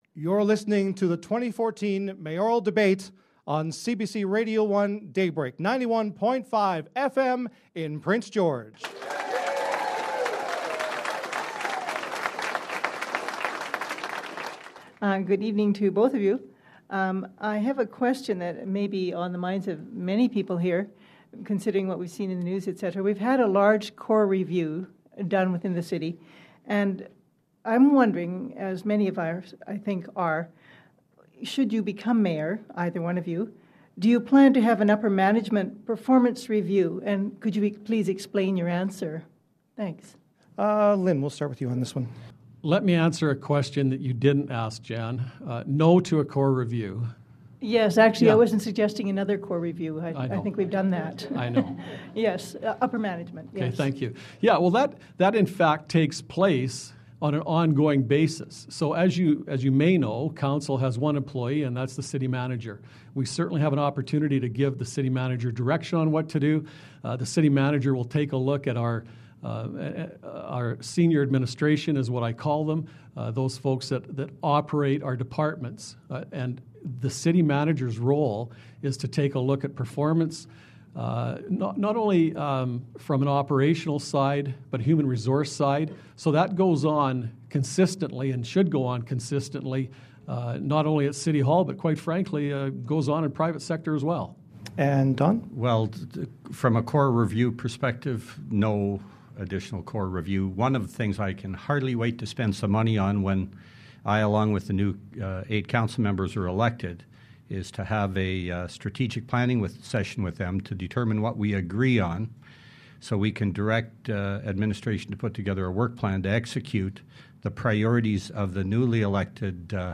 Mayoral candidates Don Zurowski and Lyn Hall answer.